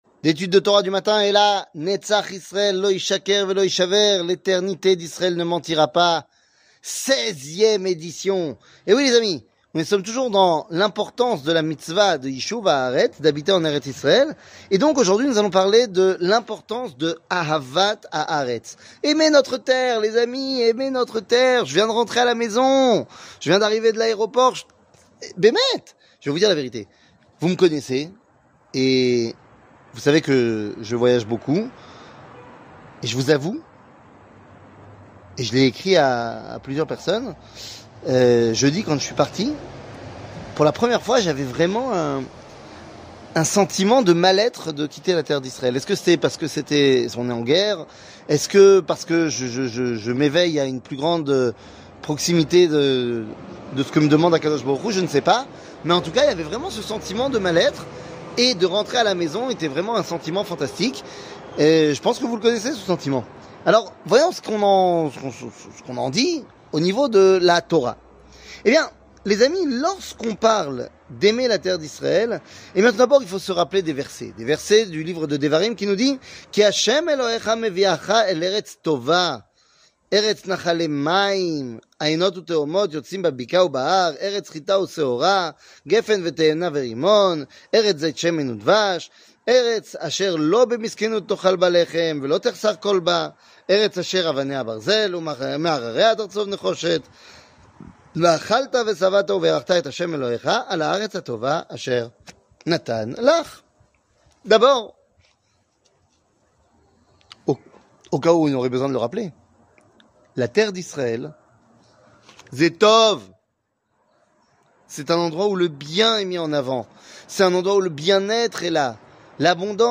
L eternite d Israel ne mentira pas ! 16 00:04:55 L eternite d Israel ne mentira pas ! 16 שיעור מ 30 אוקטובר 2023 04MIN הורדה בקובץ אודיו MP3 (4.5 Mo) הורדה בקובץ וידאו MP4 (8.94 Mo) TAGS : שיעורים קצרים